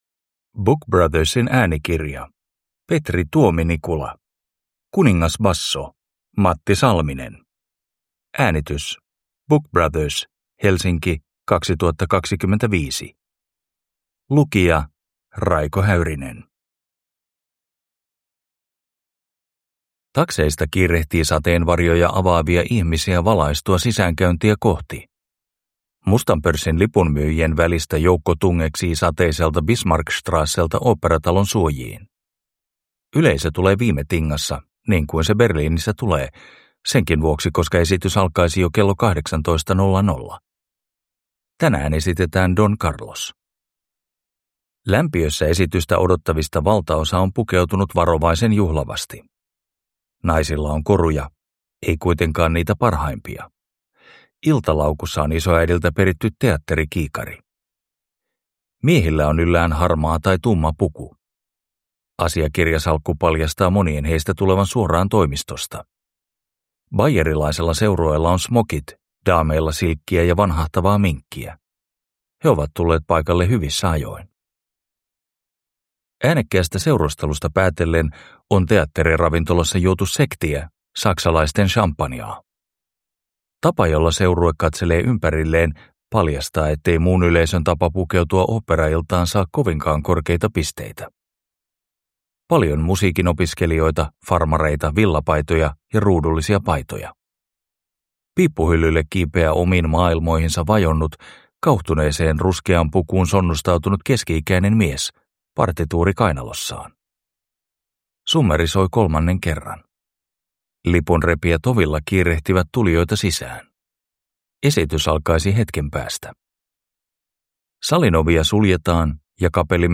Kuningasbasso Matti Salminen – Ljudbok